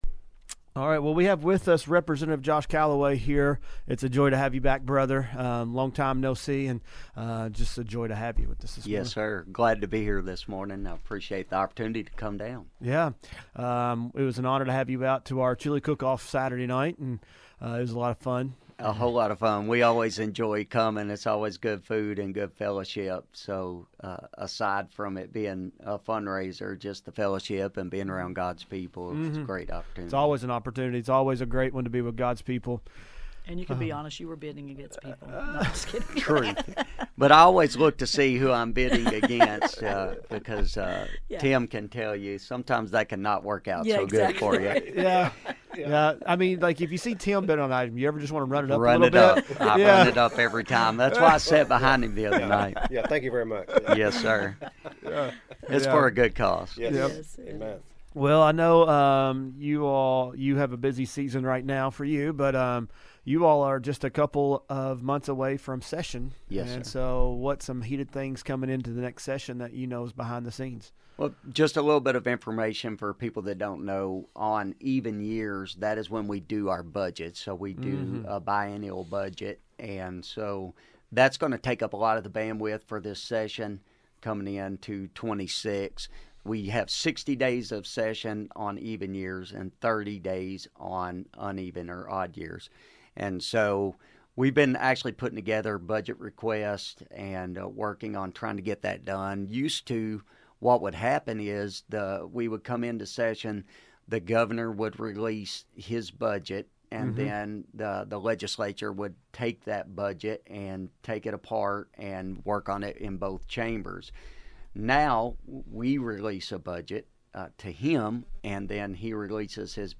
Interview with Kentucky State Representative Josh Calloway – Box 2 Radio Network
interview-with-kentucky-state-representative-josh-calloway